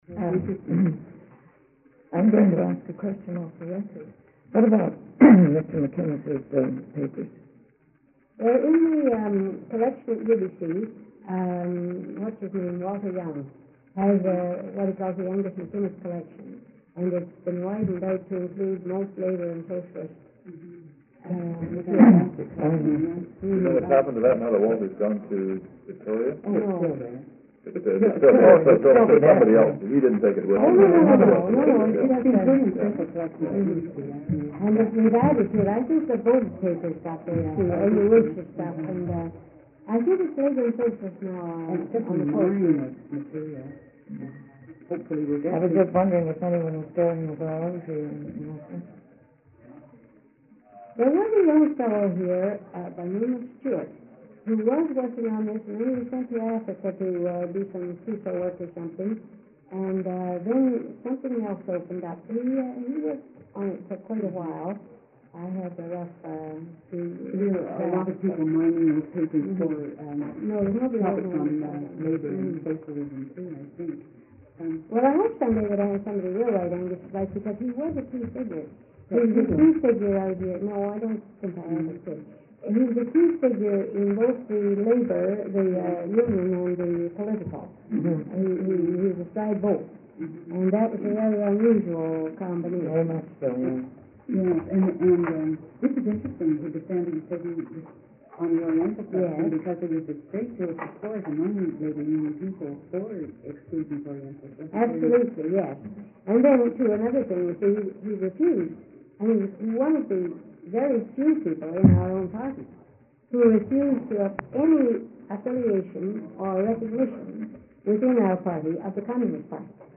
Grace MacInnis, M.L.A., reminisces about her father, J.S. Woodsworth, M.P. and reform politics in Canada during the first half of the twentieth century. Topics covered includes conscientious objecting, the Winnipeg General Strike 1919, labour radicalism, trade unionism, and West coast political militancy.,